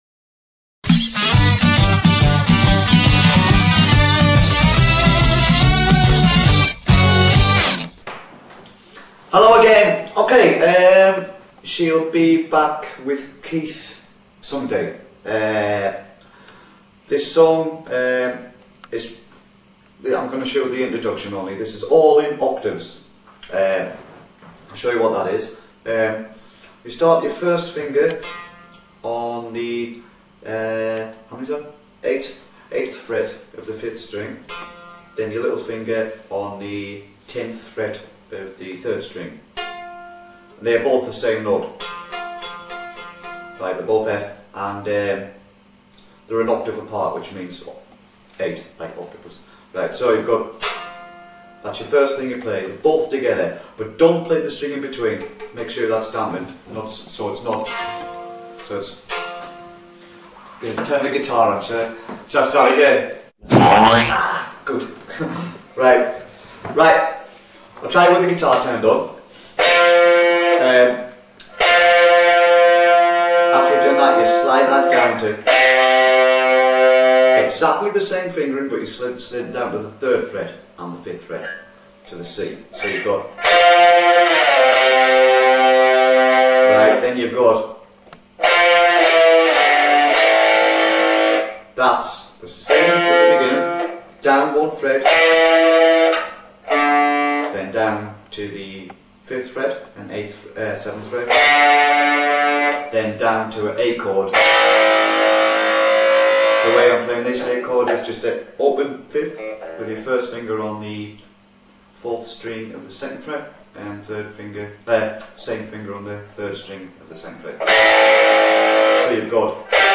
Guitar Lesson 5 - She'll Be Back With Keith Someday